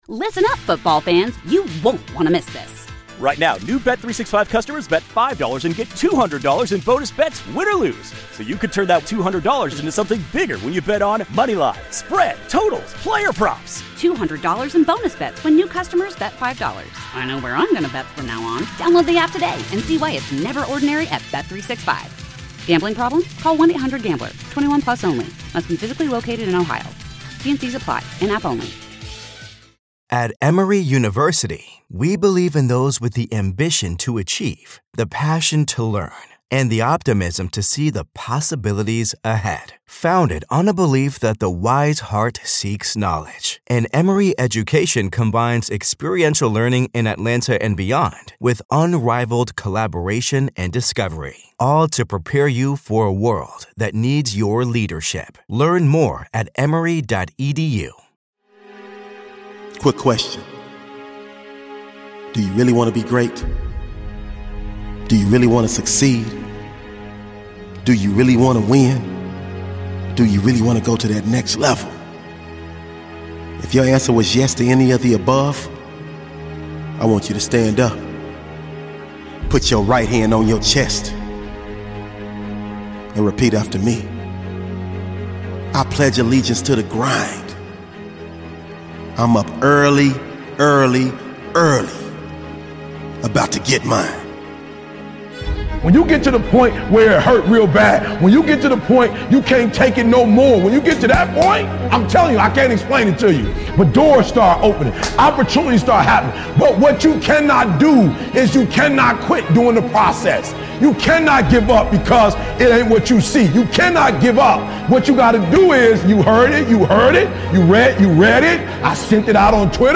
Get energized with motivational speeches, empowering words, and self-improvement strategies. Learn how discipline, gratitude, and a growth mindset fuel success and personal growth.